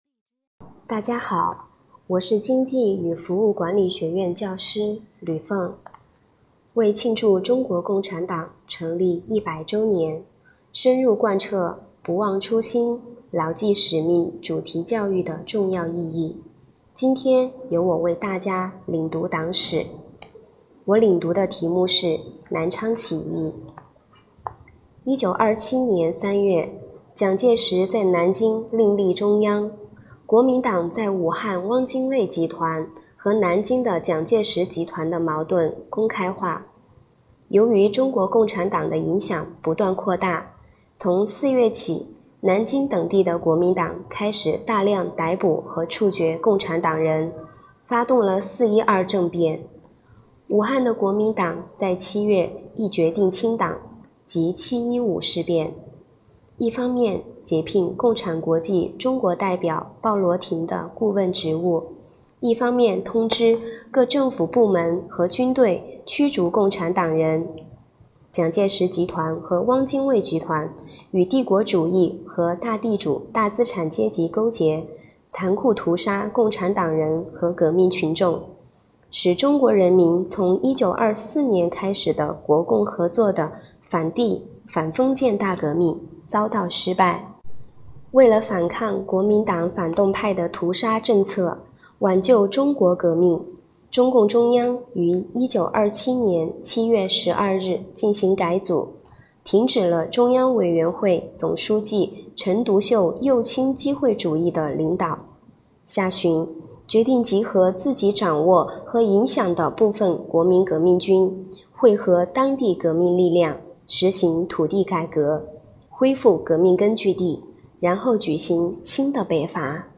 “学党史，献职教，创辉煌” ——教师领读党史系列活动（二）